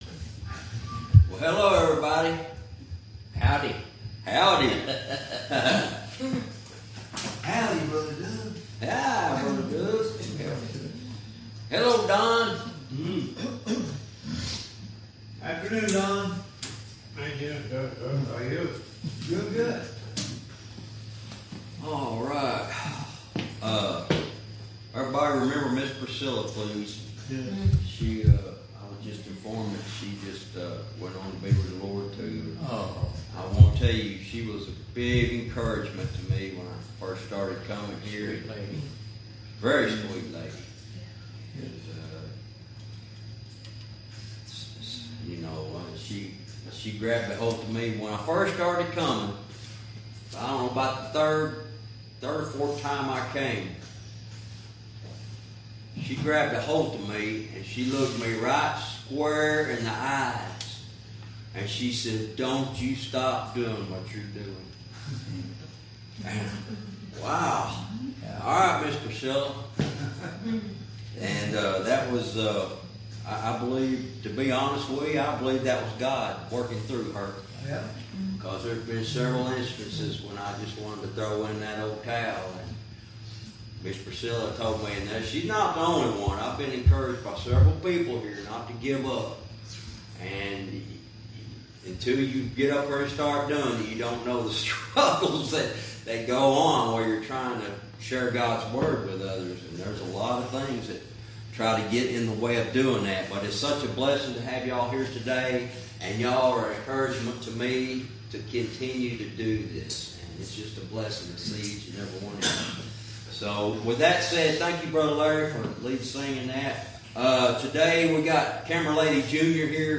Verse by verse teaching - Jude lesson 63 verse 14-15 "Ungodly Goats"